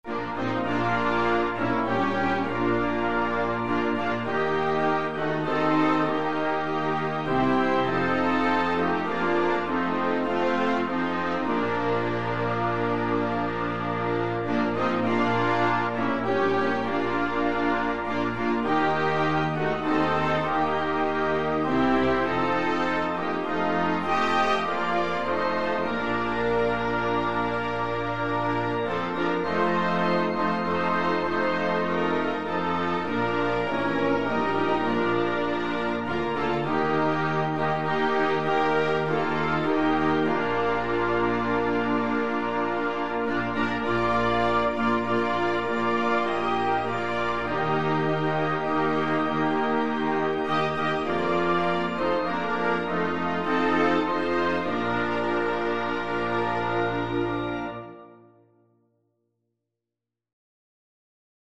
Bennard, George - The Old Rugged Cross Free Sheet music for Flexible Ensemble and Piano - 4 Players and Piano
Christian